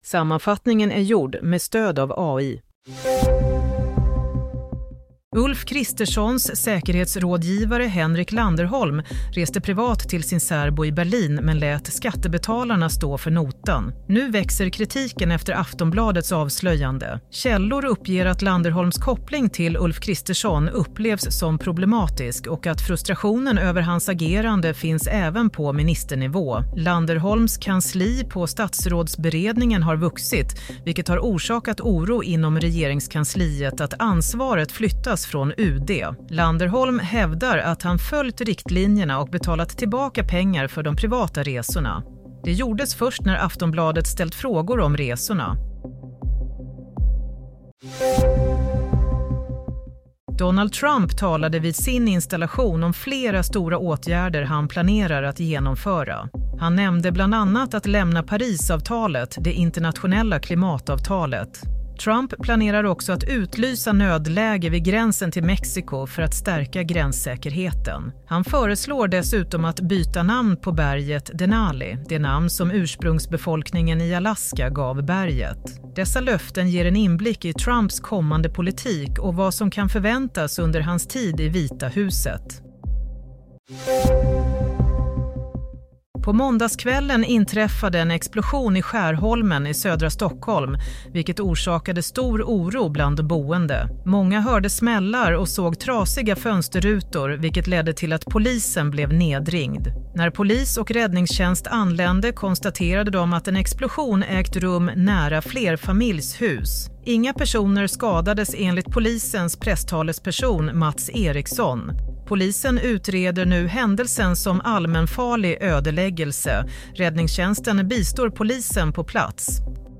Nyhetssammanfattning – 20 januari 22:00